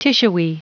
Prononciation du mot tissuey en anglais (fichier audio)
Prononciation du mot : tissuey